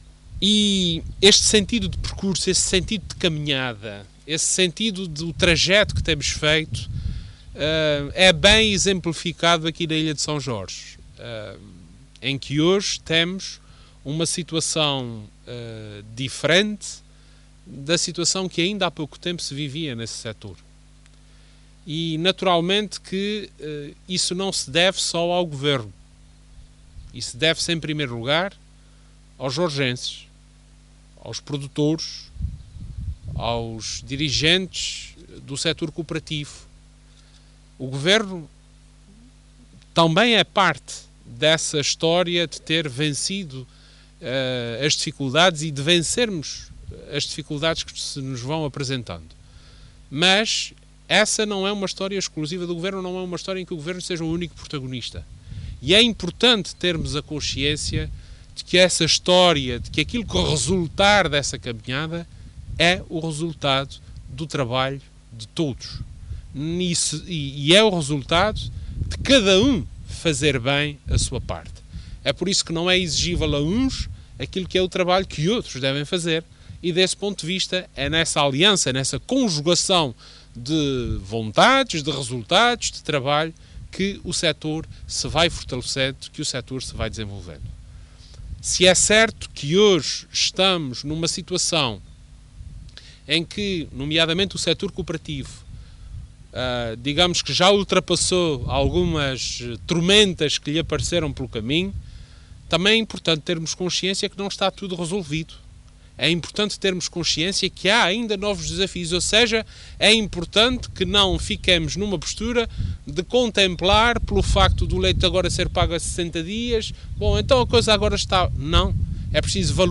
“Se é certo que hoje estamos numa situação em que o setor cooperativo já ultrapassou algumas tormentas, também é importante termos a consciência de que não está tudo resolvido”, frisou Vasco Cordeiro, que falava na inauguração do furo e captação de água na Ribeira do Nabo, em São Jorge.